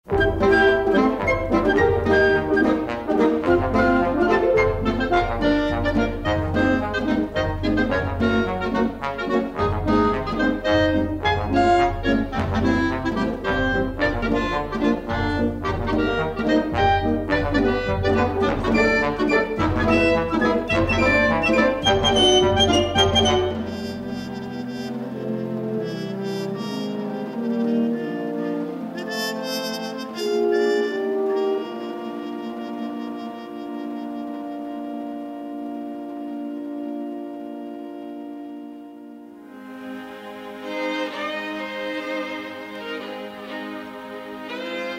a sly, comic theme for saxophones